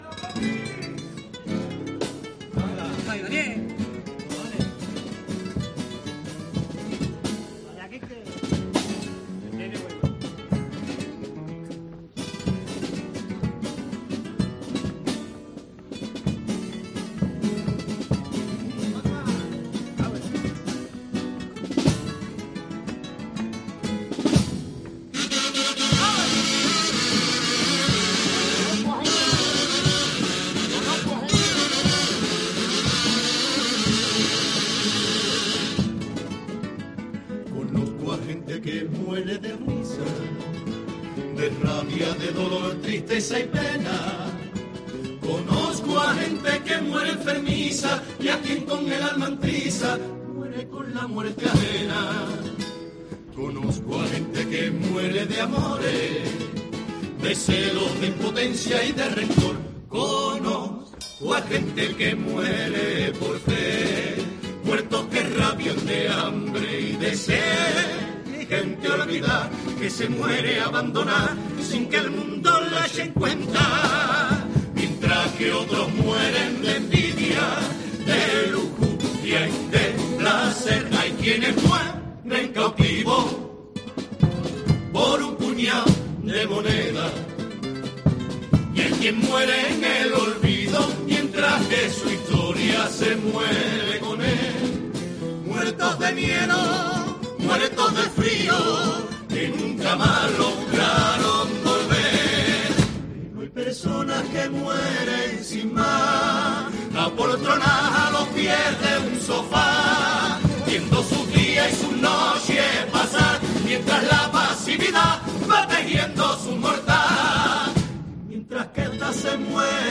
en una de las letras de su actuación en el Falla
Pasodoble 'carpe diem' de la comparsa 'Los peliculeros'
Un pasodoble melódico y una letra clara en la que la agrupación reivindica la necesidad de disfrutar cada momento como si fuera el último.